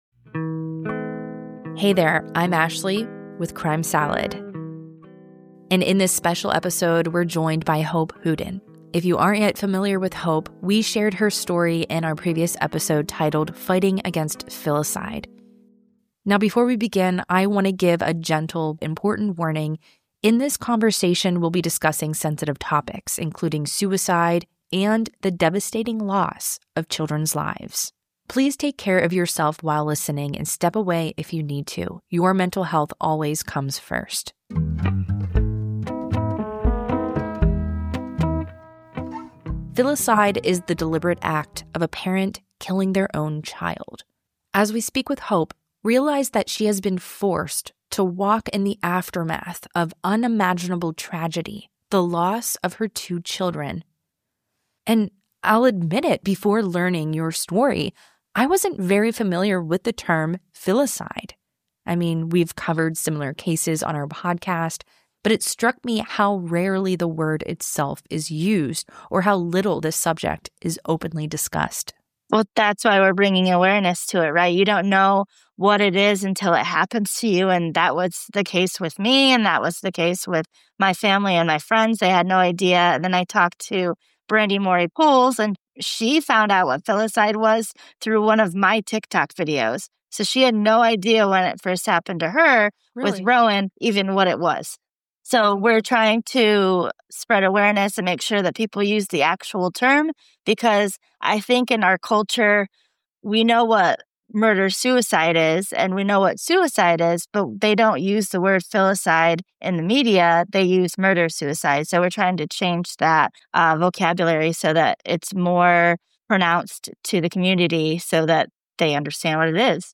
Filicide Awareness Interview